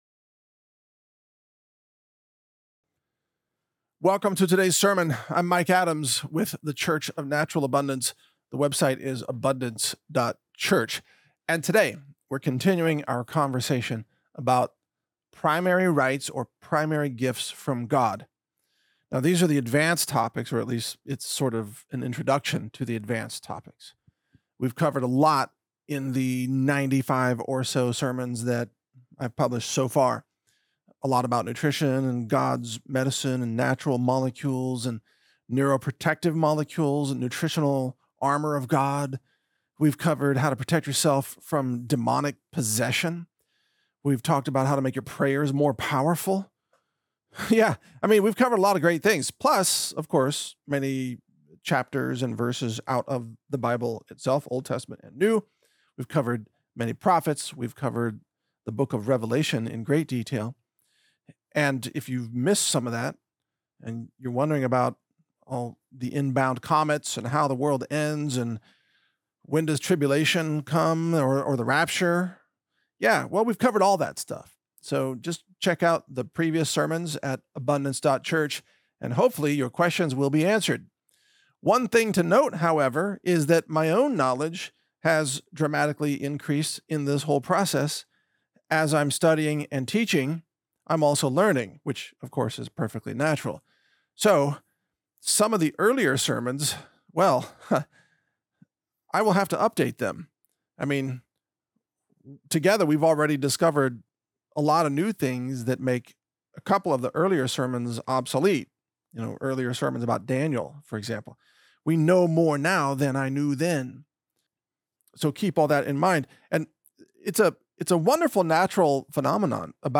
Mike Adams Sermon #095 - God's gift of CONSCIOUSNESS - Spawning the multiverse, quantum realities and the power of FREE WILL - Natural News Radio